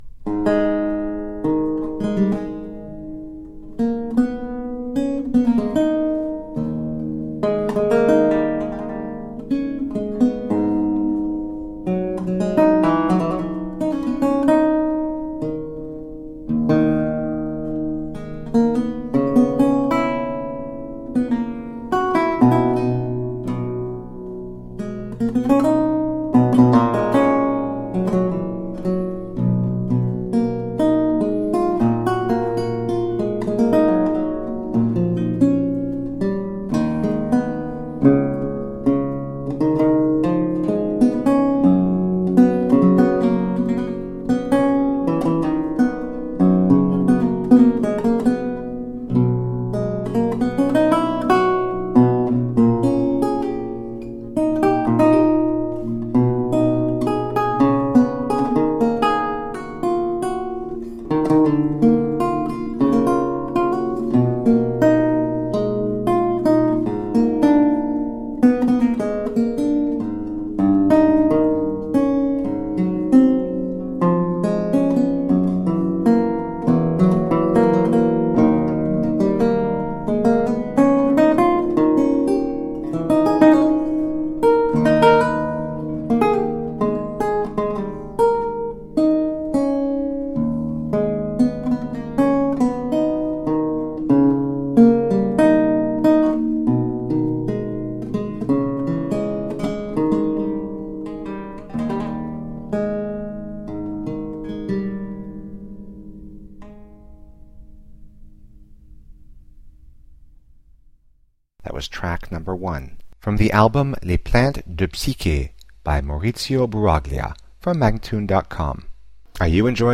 A marvelous classical spiral of lute sounds.
Classical, Baroque, Instrumental Classical
Lute